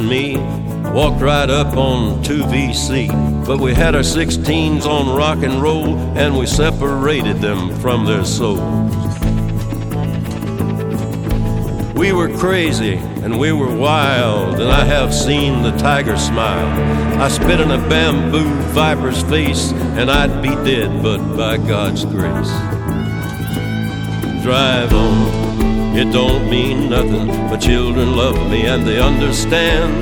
Guitare
country music